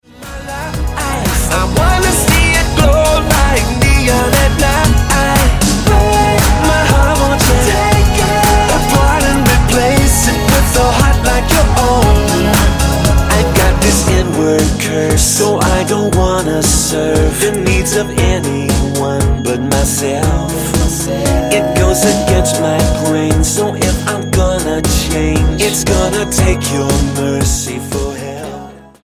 Dance/Electronic
Style: Pop